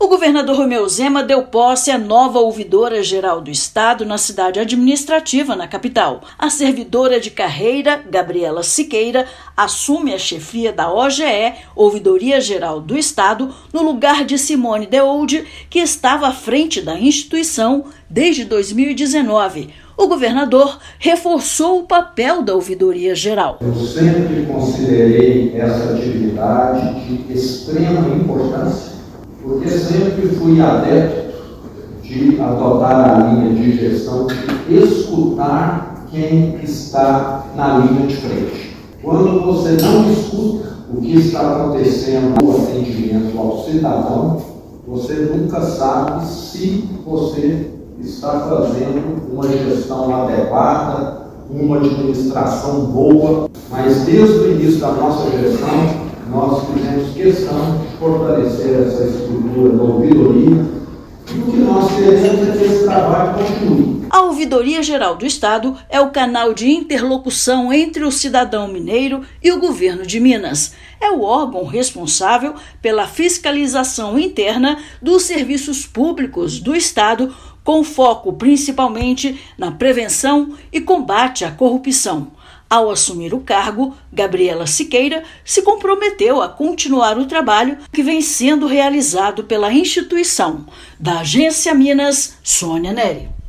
Gabriela Siqueira assume a Ouvidoria-Geral do Estado de Minas Gerais (OGE/MG) com a missão de continuar o trabalho de garantir uma gestão pública transparente, íntegra e responsável. Ouça matéria de rádio.